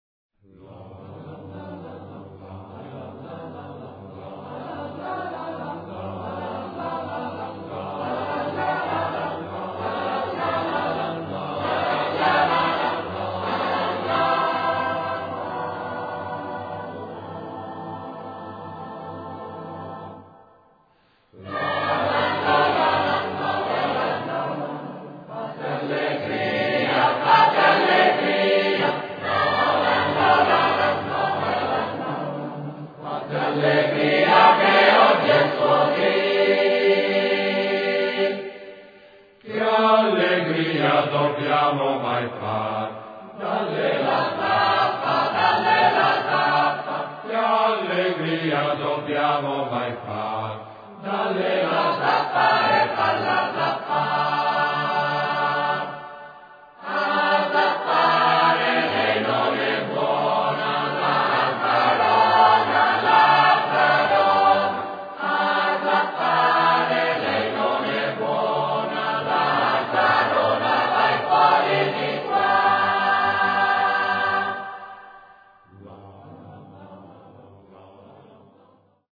Lazzarona - Coro Stelutis